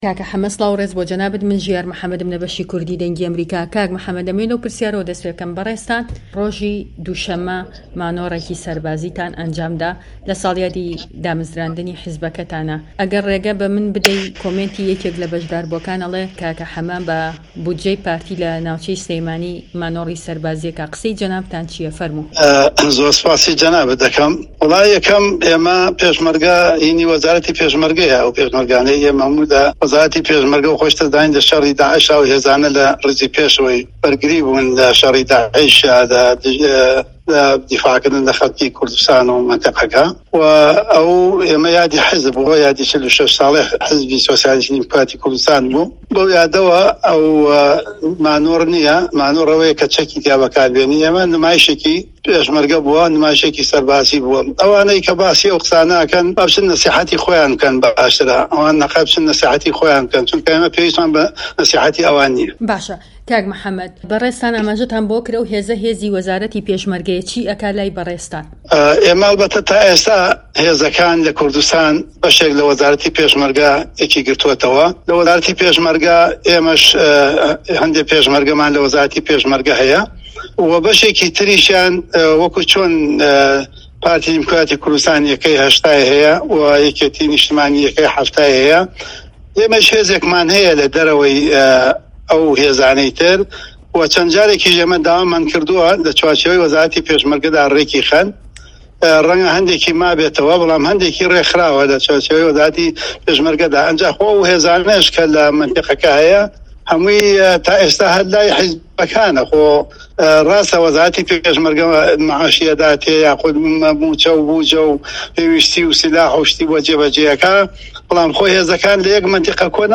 دەقی وتووێژەکەی محەمەدی حاجی مەحمود